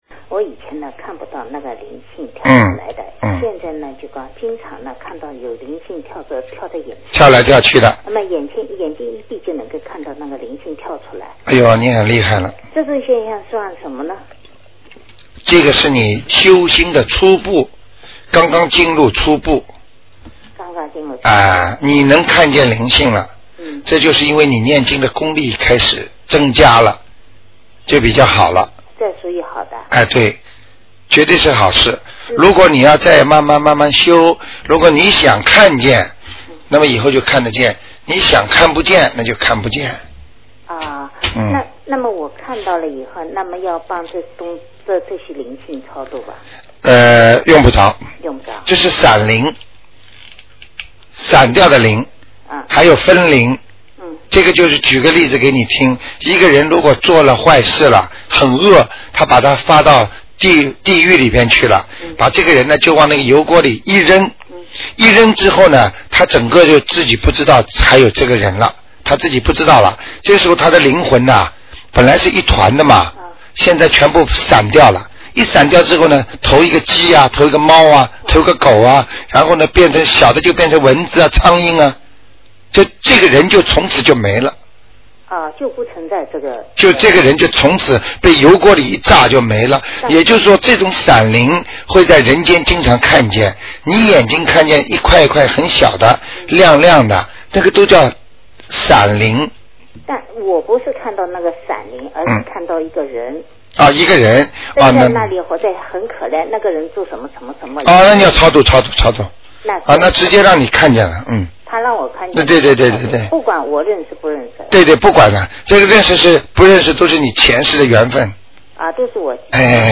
2. 录音文字整理尽量保持与原录音一致。但因对话交流带有语气，文字整理不可能完全还原情境。